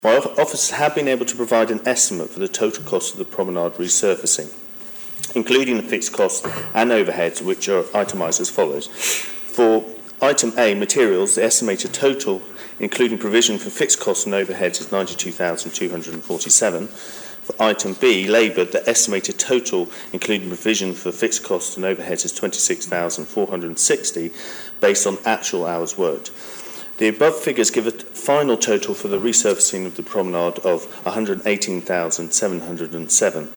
This week in Tynwald, Infrastructure Minister Ray Harmer faced questions about the total amount spent after a press release from his department estimated it would cost around £75,000.
Mr Harmer said the true cost had been significantly higher: